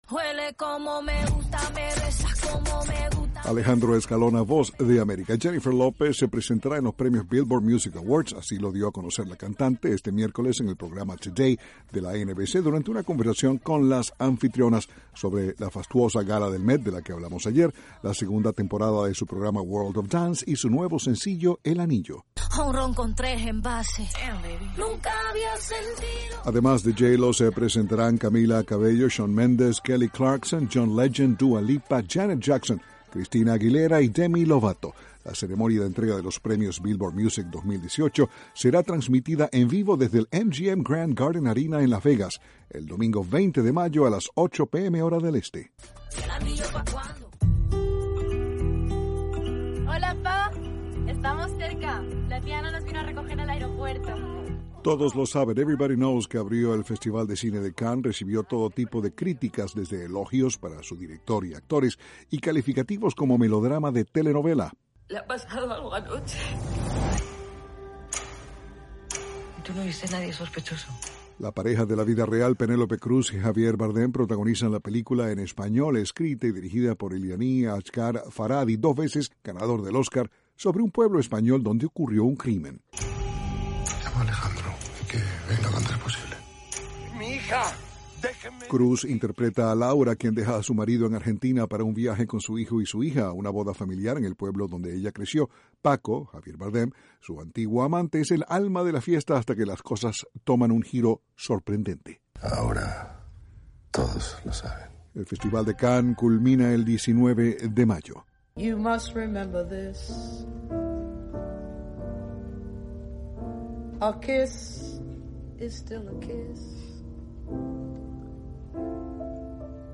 informa desde Washington...
Es Carmen McRae, grabada en vivo, voz y piano, en el legendario Dug, en Tokio, en 1973, que hoy es un bar decorado con ladrillos rojos y fotografías de jazzistas que frecuentaban el local hace 40 o 50 años.